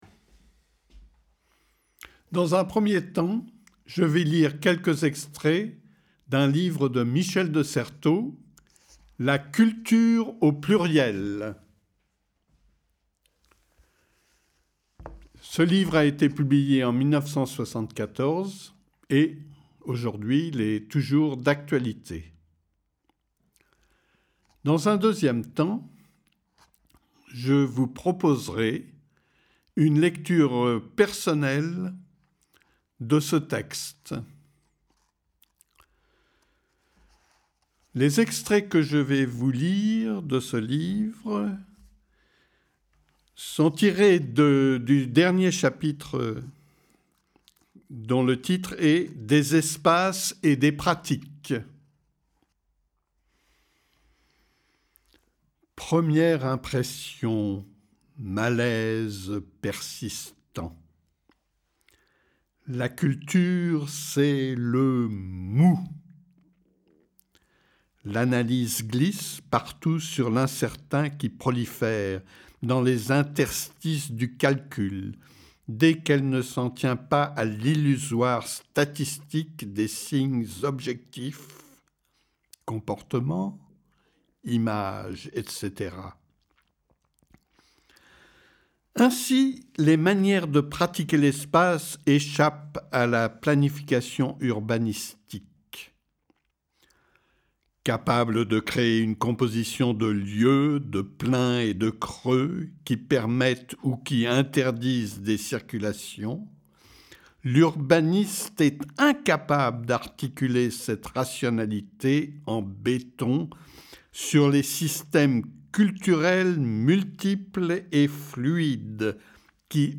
[ 0’00 présentation ; 0’54 lecture ; 7’12 slam ]